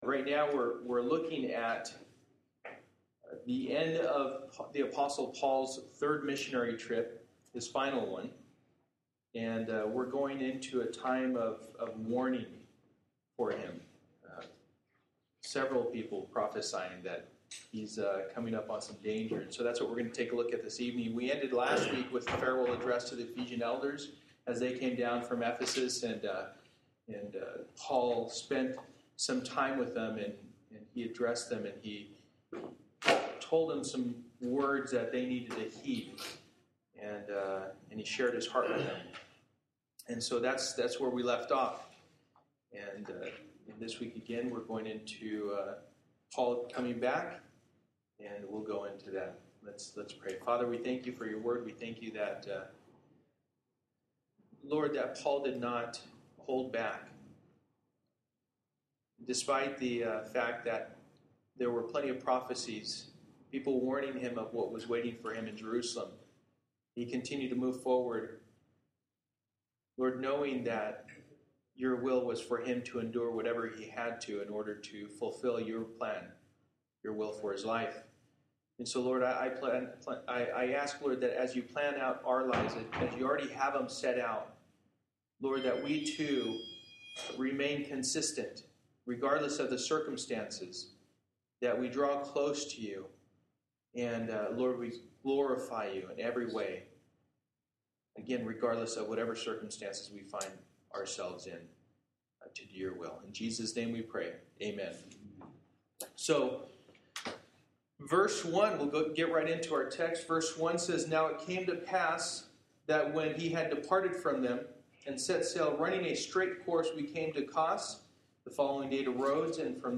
Passage: Acts 21:1-40 Service: Wednesday Night